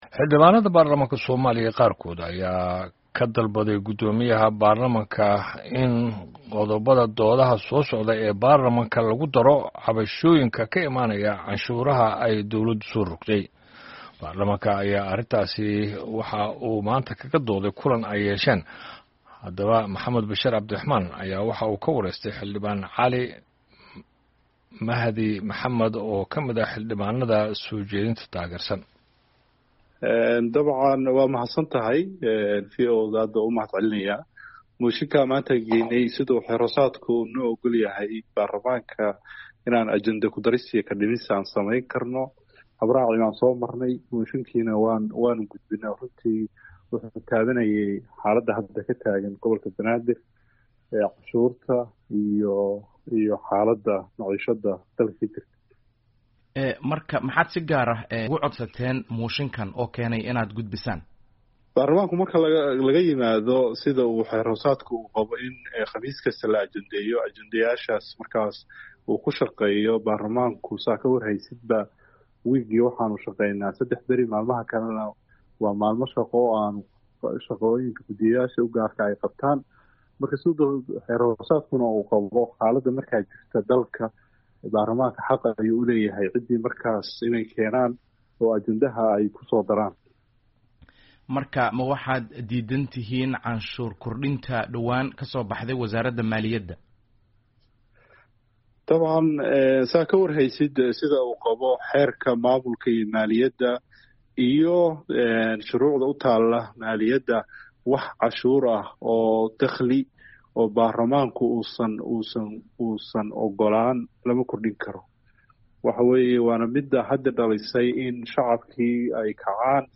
Xildhibaano si adag uga hadlay canshuur kordhinta, wareysi
Wareysi Xildhibaan Cali Mahdi